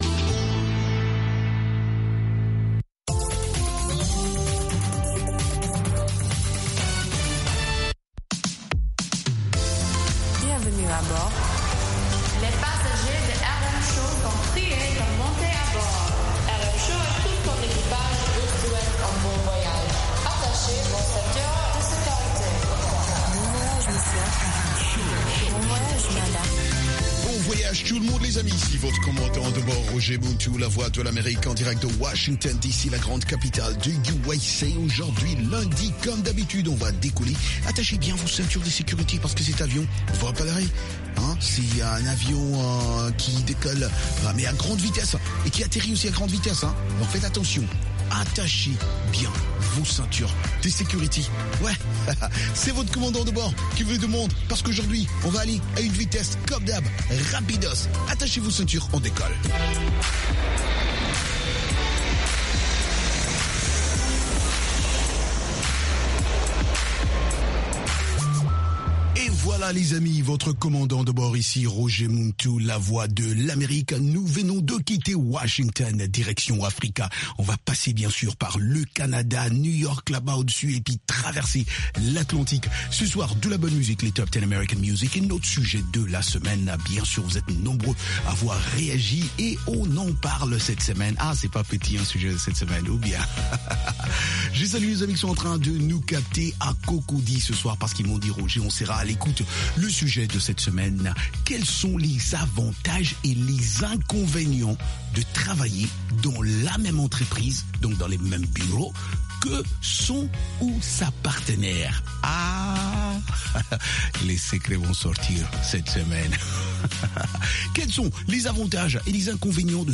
RM Show - Le hit-parade Americain